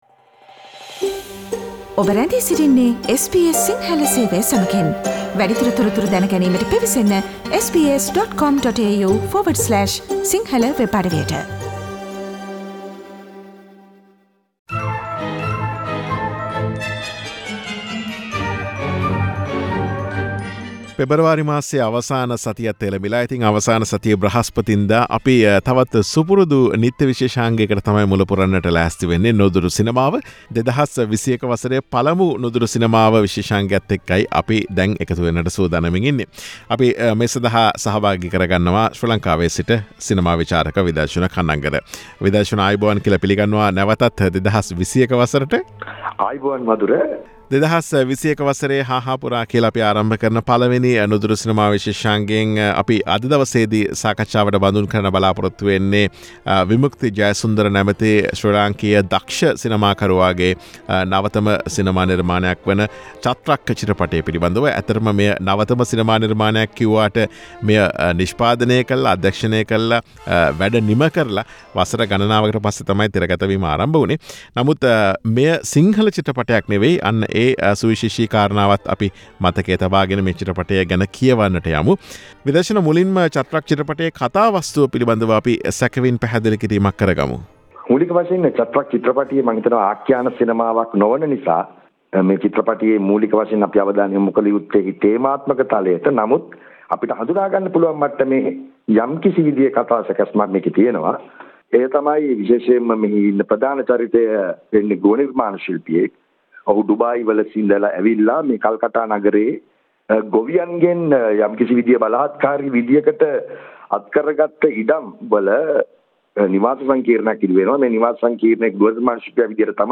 සිනමා විචාරක